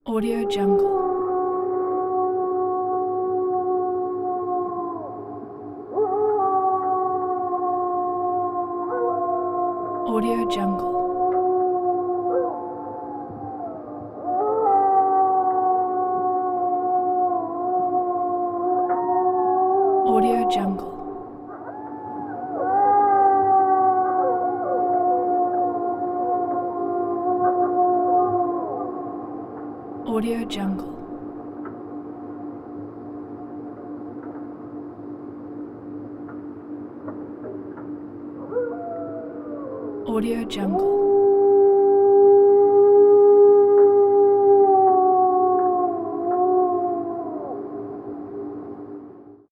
Pack Howling Chorus Bouton sonore